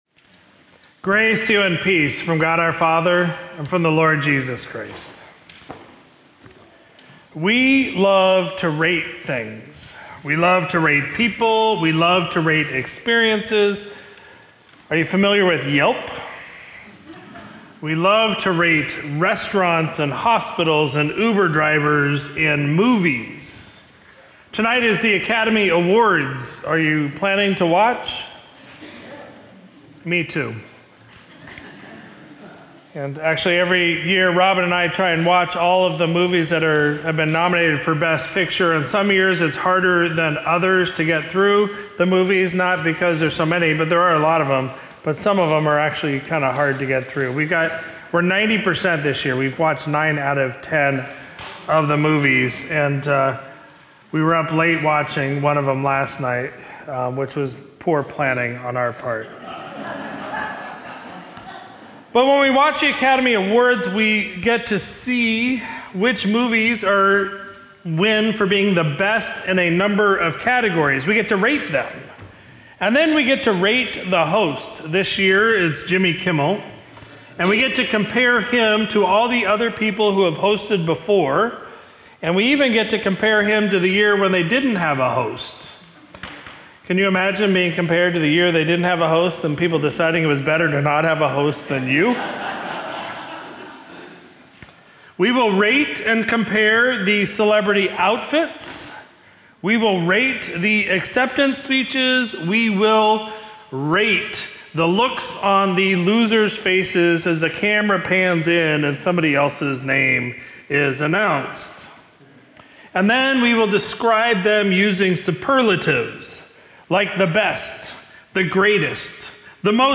Sermon from Sunday, March 10, 2024